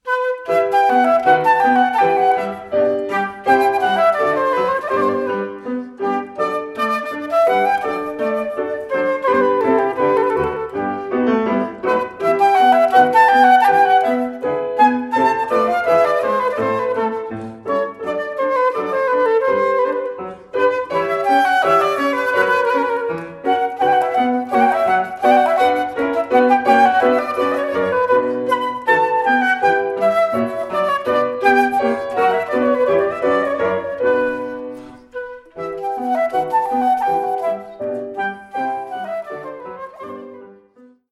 Flöte und Klavier